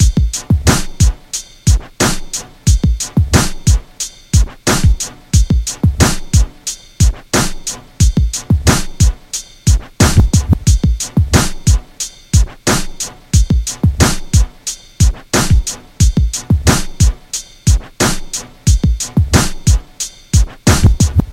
90年代嘻哈节拍2
描述：嘻哈节拍 90bpm
标签： 90 bpm Hip Hop Loops Drum Loops 3.59 MB wav Key : Unknown
声道立体声